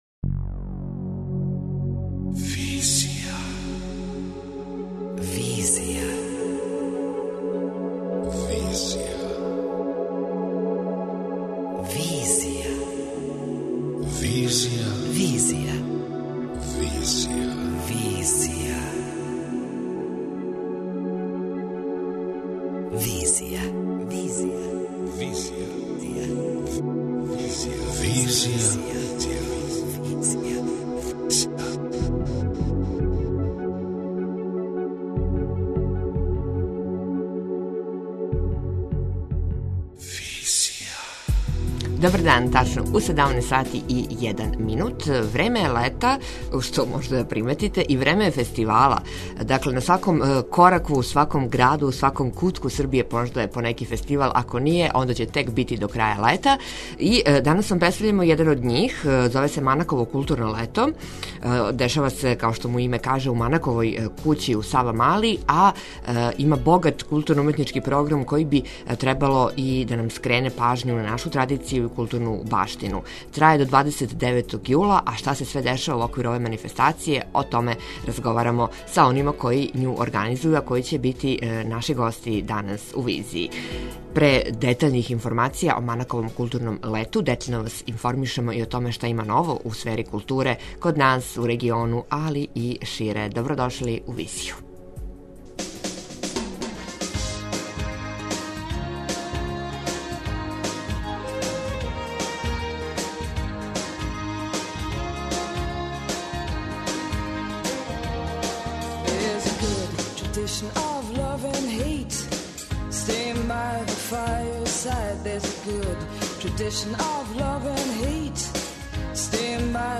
О Манаковом културном лету разговарамо са нашим гостима из Етнографског музеја, који је организатор манифестације.